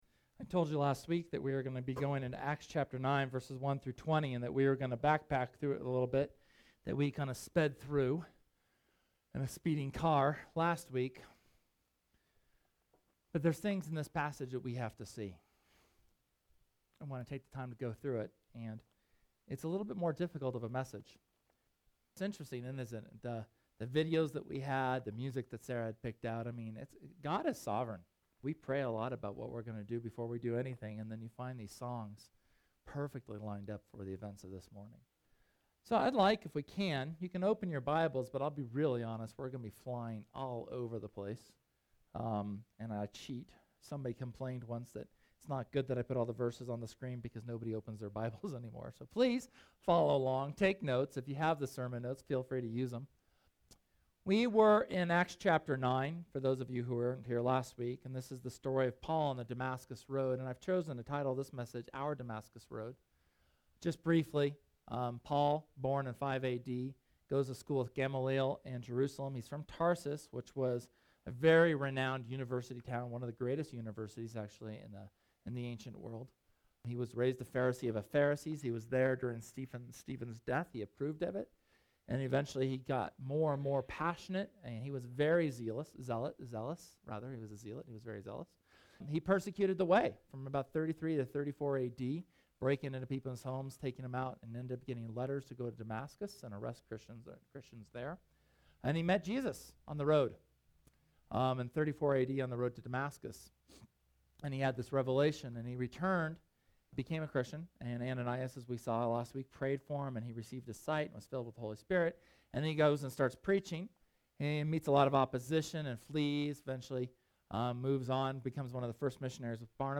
SERMON: Our Damascus Journey – Church of the Resurrection